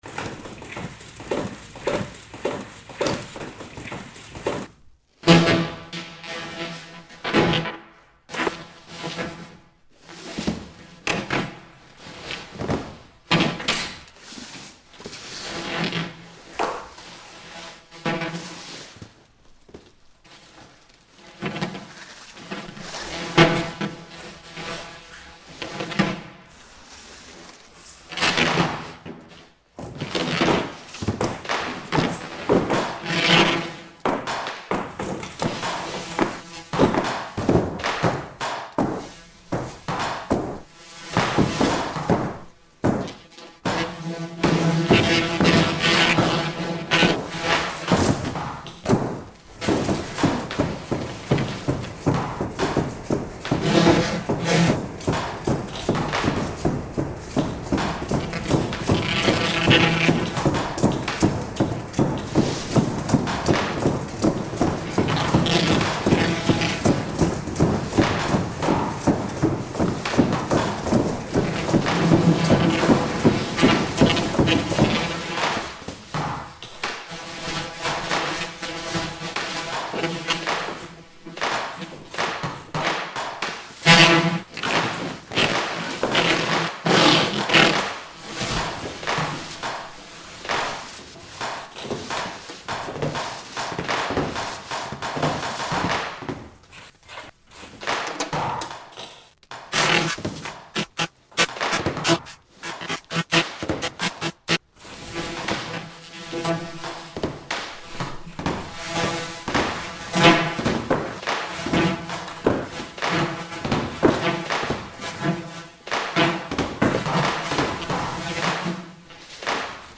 Version for wind instruments, dancers, video and playback.
Sound Art Series